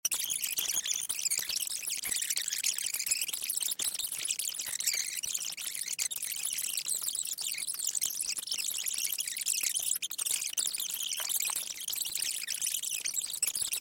fast forward vhs Meme Sound Effect
fast forward vhs.mp3